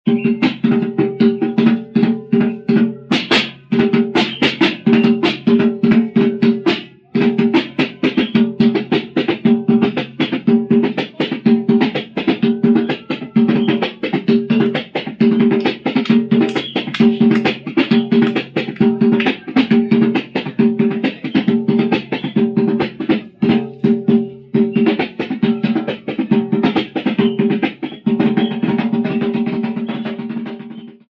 Tambor cujo corpo arredondado é feito em madeira; uma das extremidades é coberta por membrana fixada por cordas e cunhas. Pode ser percutido com as mãos ou com baquetas.
Atabaques
autor: Grupo Joel Lourenço, data: 1977
atabaque.mp3